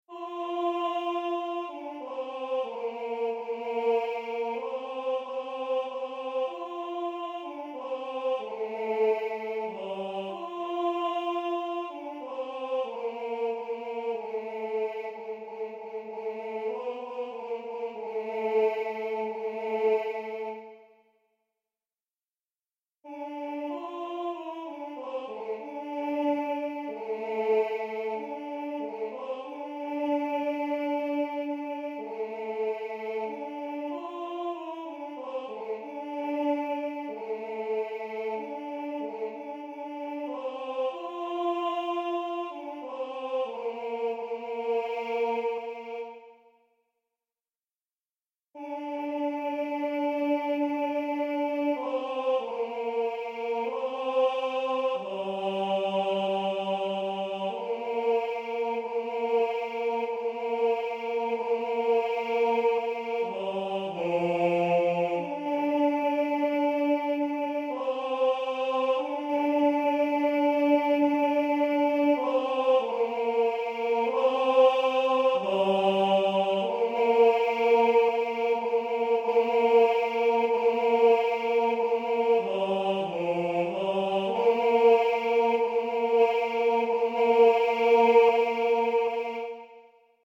Partitions et enregistrements audio séquenceur du morceau Deep River Rhapsody, de Traditionnel, Negro spiritual.
deepriverrhapsody-tenor_0.mp3